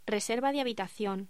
Locución: Reserva de habitación
Sonidos: Voz humana
Sonidos: Hostelería